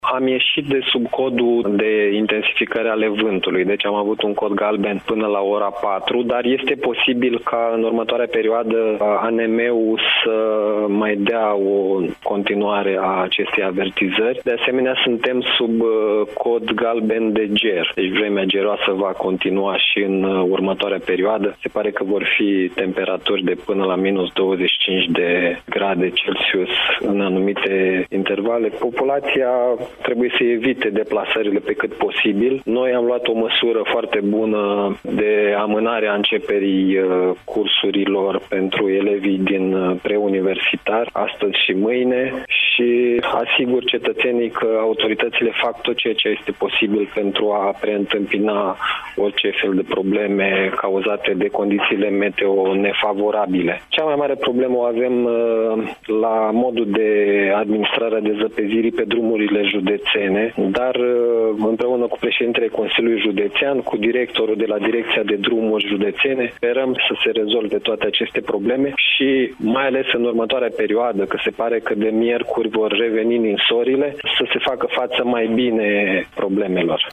Prefectul de Iași, Marian Grigoraș, ÎN DIRECT la Radio Iași – Tema Zilei